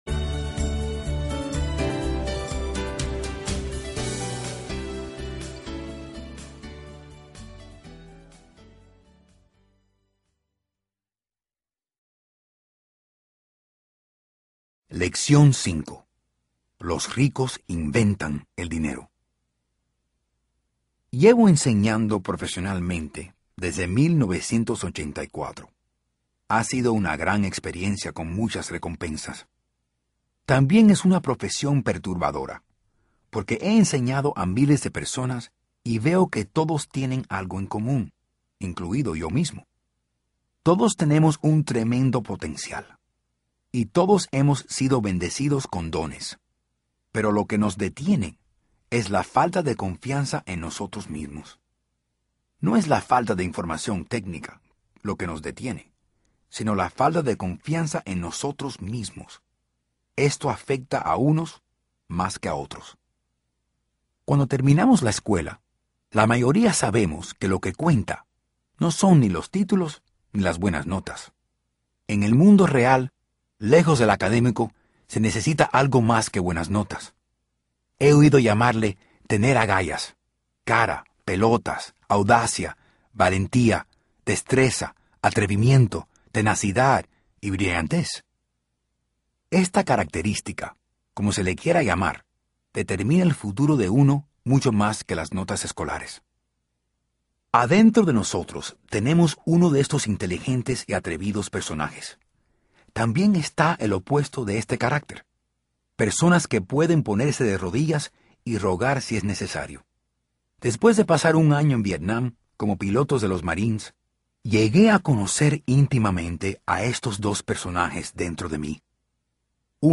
181.-PADRE-RICO-PADRE-POBRE-Audiolibro-Parte-3-Robert-Kiyosaki.mp3